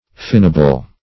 Search Result for " finable" : Wordnet 3.0 ADJECTIVE (1) 1. liable to a fine ; [syn: finable , fineable ] The Collaborative International Dictionary of English v.0.48: Finable \Fin"a*ble\, a. [From Fine .]